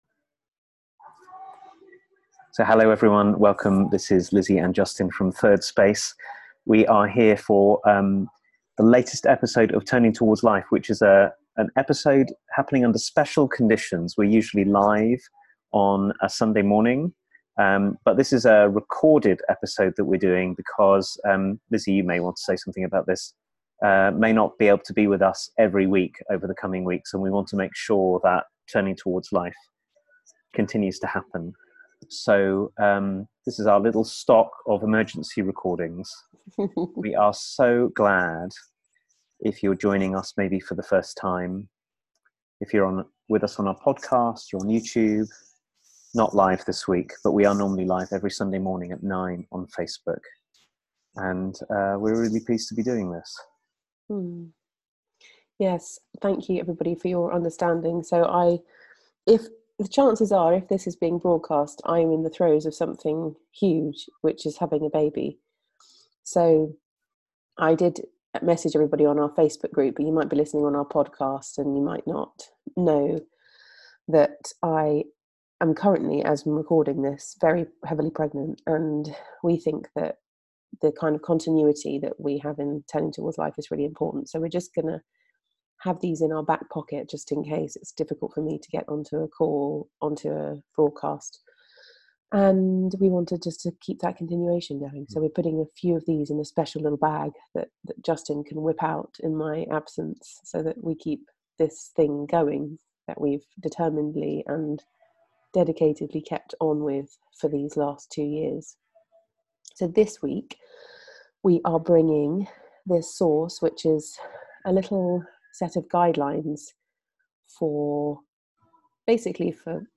A conversation about a wild, courageous way to listen to friends, lovers, family, colleagues and those with whom we disagree most profoundly